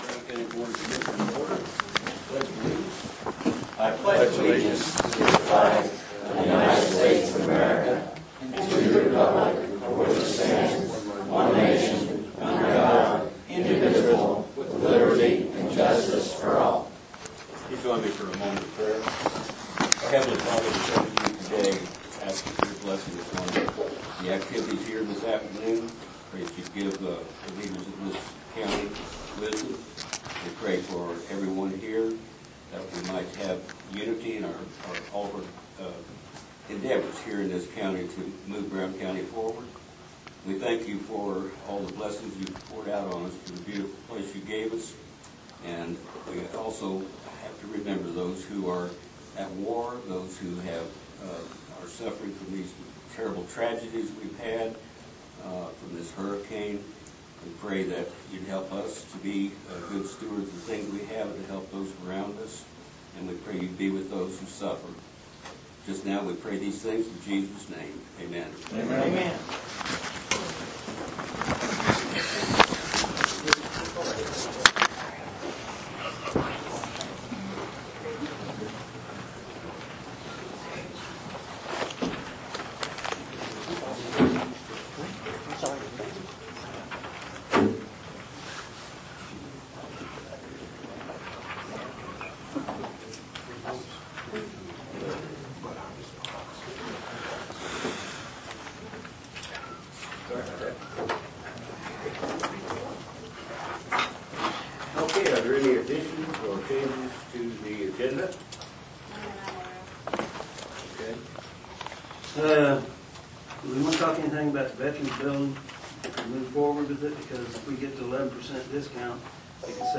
Commissioner Meeting Notes, Oct 2, 2024, 2:00 – 4:00